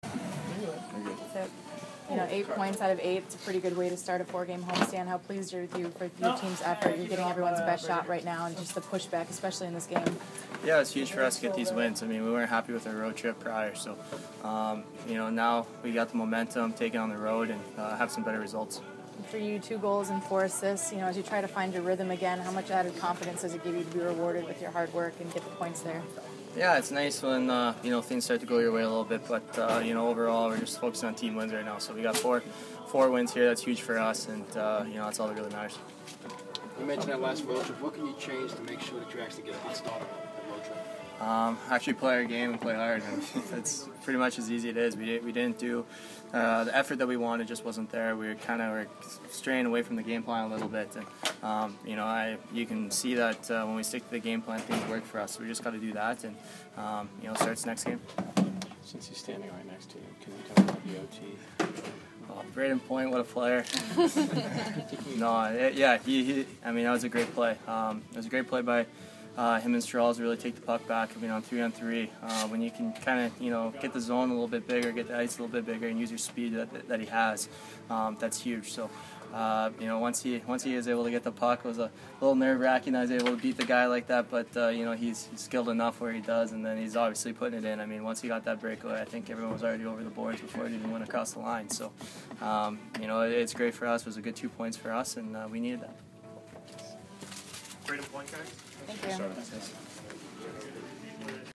Tyler Johnson Post-Game 12/9